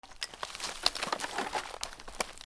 古びた木片 物音
/ M｜他分類 / L01 ｜小道具 /
『ゴソゴソ ミシミシ』